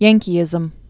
(yăngkē-ĭzəm)